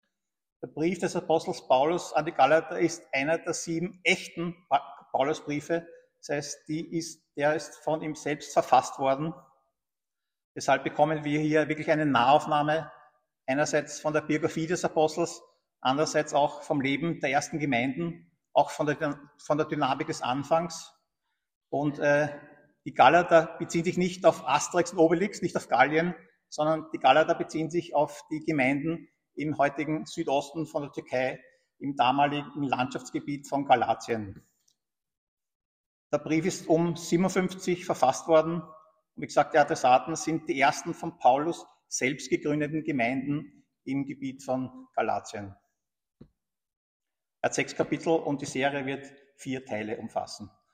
Einführung in die Predigtreihe: Paulus, unerträglich? • Predigtserie zum Galaterbrief